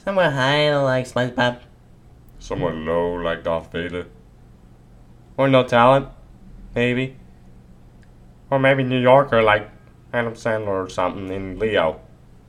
Voice Acting Audition?
These are the voices that I provide -